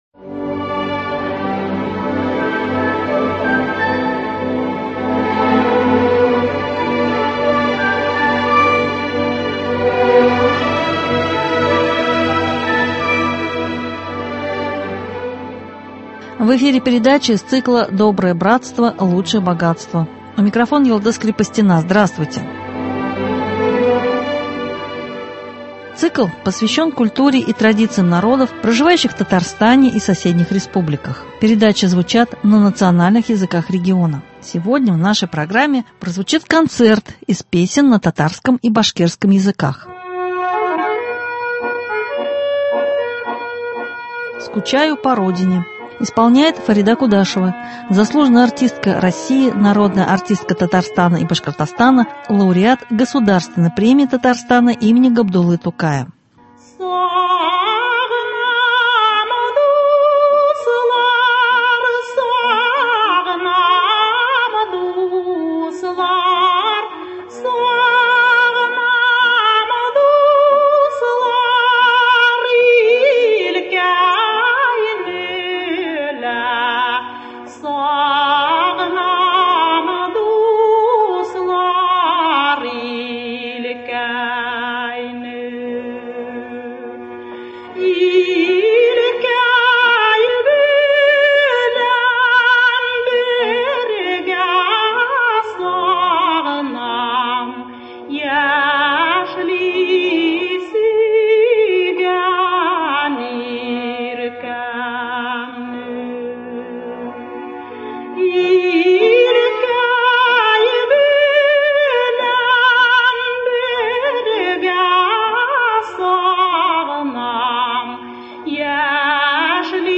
Концерт из песен на татарском и башкирском языках.